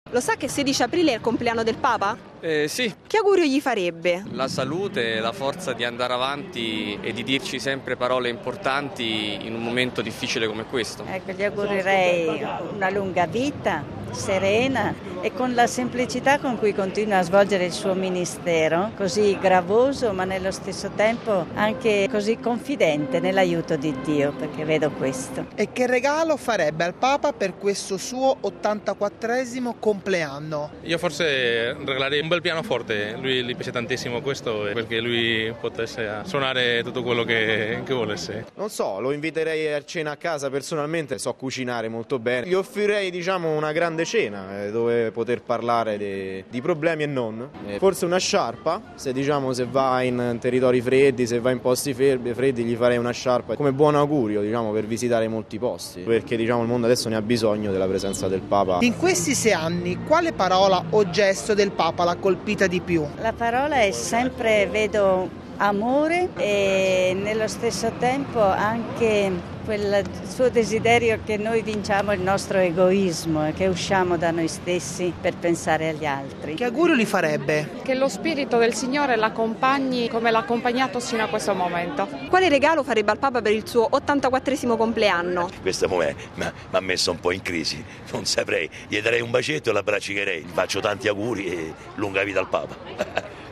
Alla vigilia della Domenica delle Palme, in una soleggiata giornata primaverile, sono tanti i fedeli e turisti che oggi si trovano in Piazza San Pietro e in via della Conciliazione.